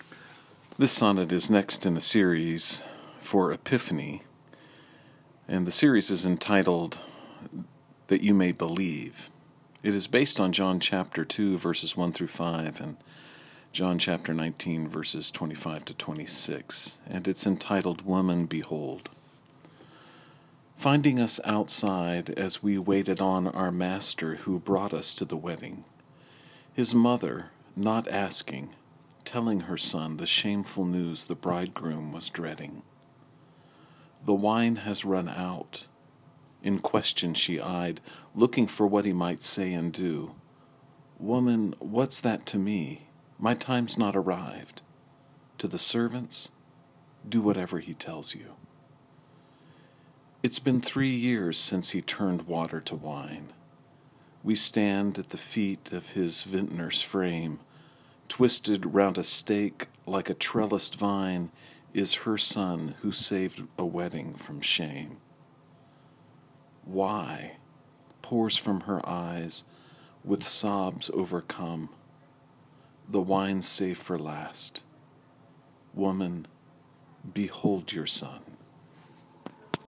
If helpful, you may listen to me read the sonnet via the player below.